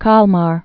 (kälmär, kăl-)